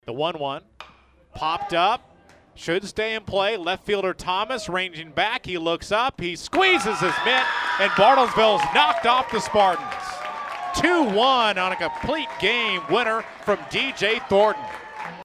Here is how the final call sounded on KWON.